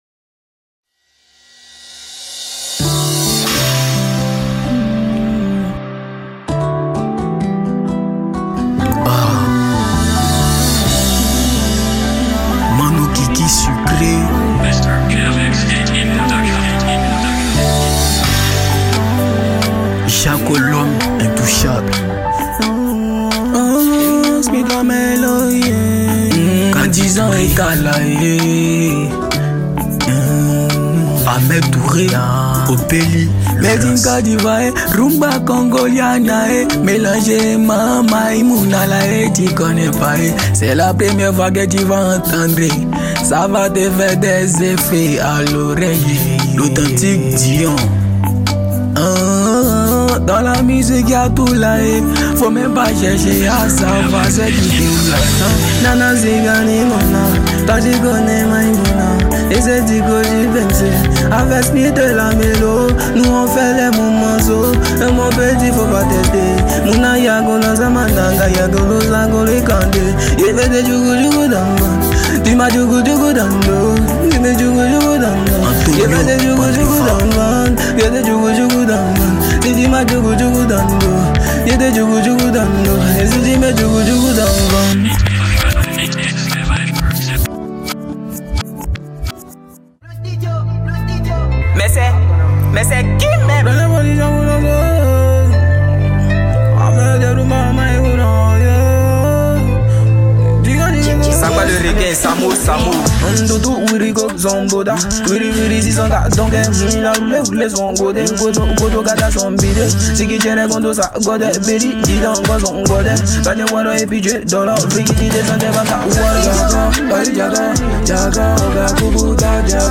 | Rap Hip-Hop